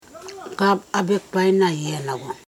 [aŋ aitaiyamamago] v. paradigm example You (pl) are looking at us (excl)